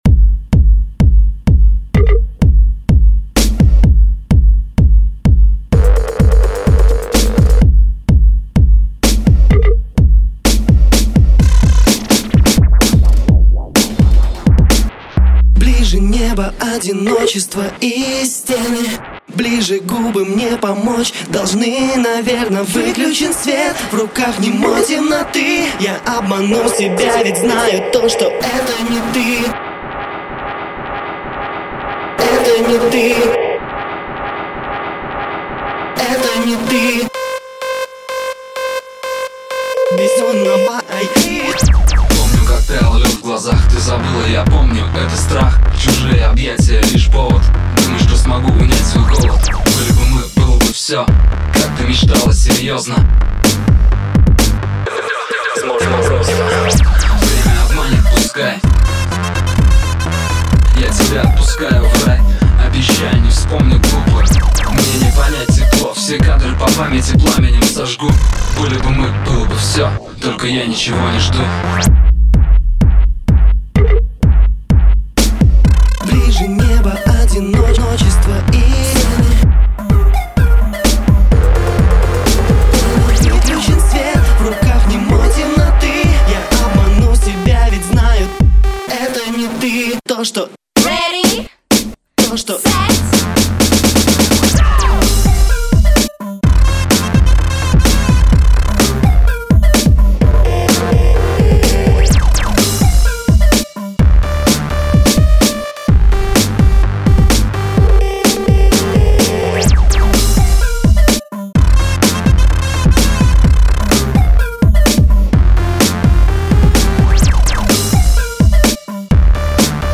Конкурс ремиксов